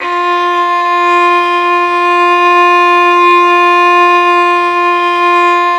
Index of /90_sSampleCDs/Roland - String Master Series/STR_Violin 4 nv/STR_Vln4 % + dyn